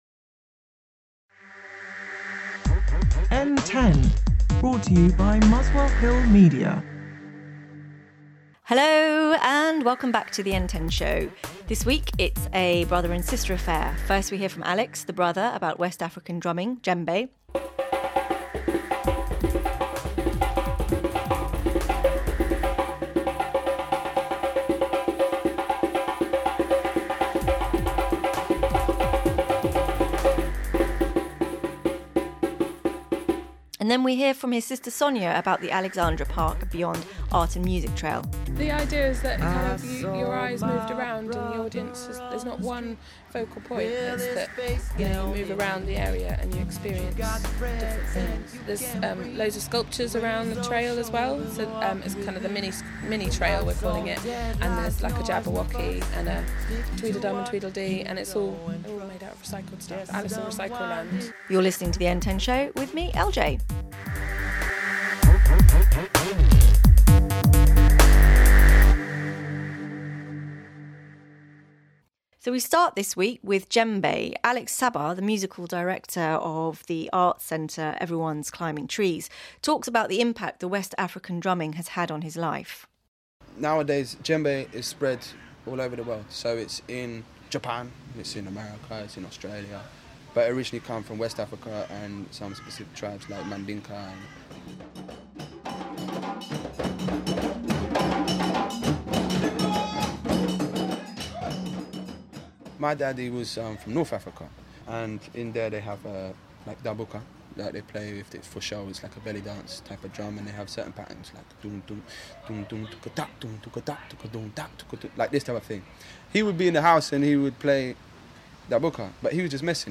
On this week's show: West African drumming and an arts trail called Alexandra Park and Beyond